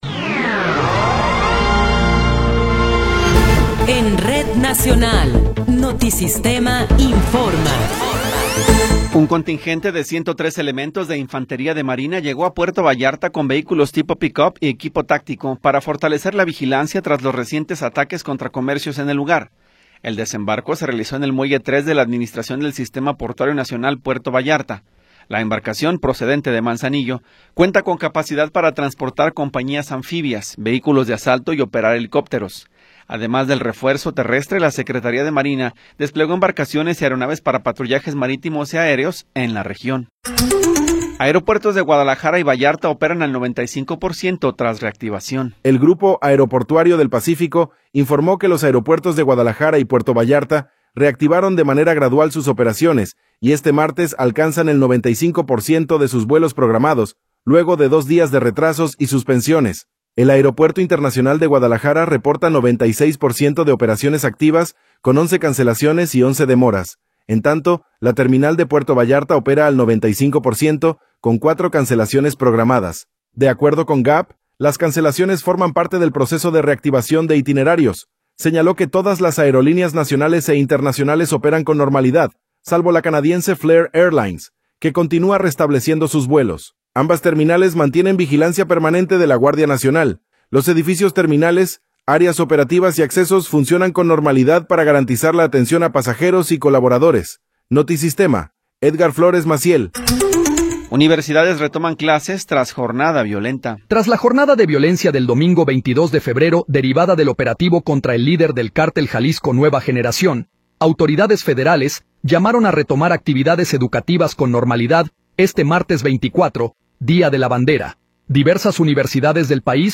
Noticiero 11 hrs. – 24 de Febrero de 2026
Resumen informativo Notisistema, la mejor y más completa información cada hora en la hora.